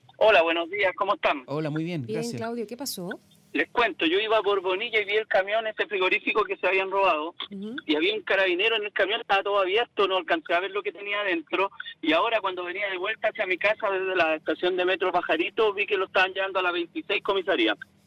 Un auditor llamó a Radio Bío Bío para relatar el momento en que Carabineros encontró el camión.